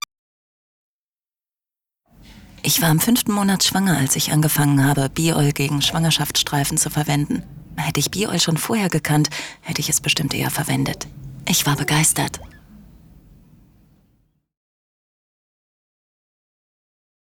Stimme: Warm, charismatisch, charmant.
Sprechprobe: Sonstiges (Muttersprache):